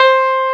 CLAV2HRDC5.wav